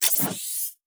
Cybernetic Technology Affirmation.wav